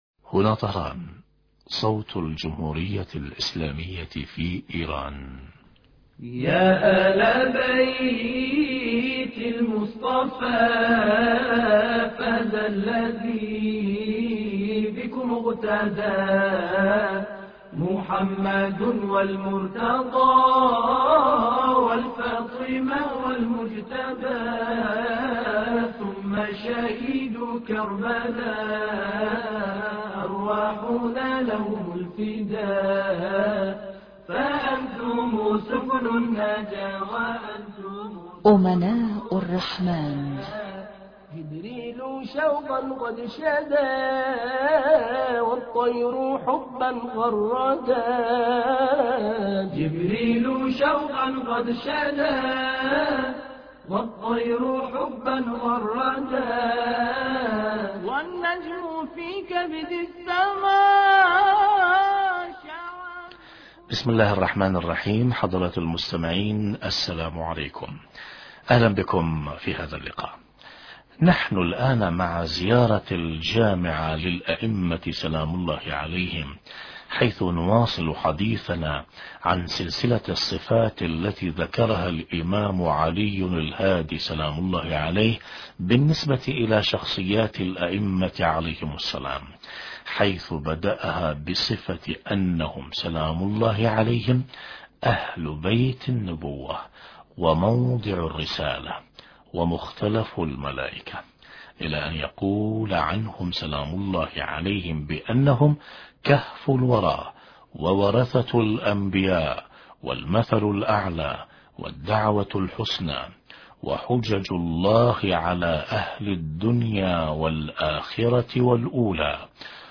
أما الآن نتابع تقديم برنامج امناء الرحمن بهذا الاتصال الهاتفي مع سماحة